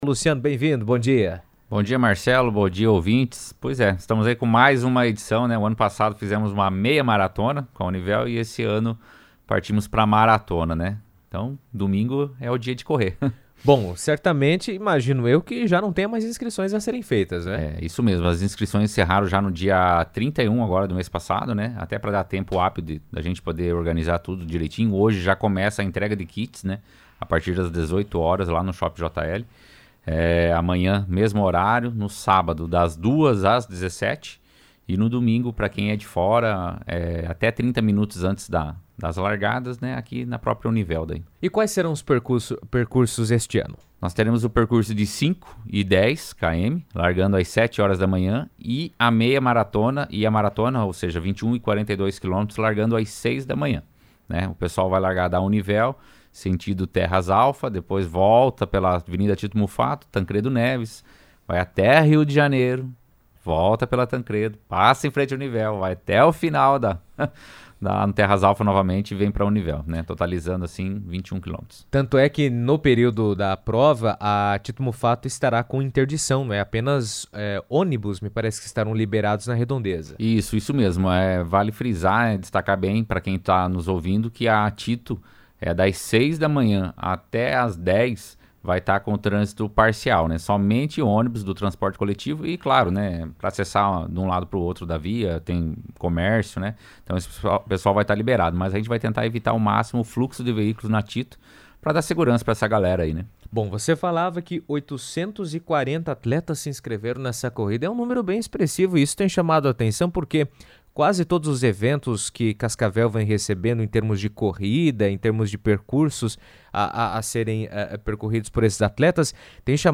A Maratona Velho Oeste Univel será realizada no próximo domingo, dia 12, em Cascavel, reunindo mais de 800 atletas em percursos de diferentes distâncias, com foco na promoção da saúde, do esporte e da superação. Em entrevista à CBN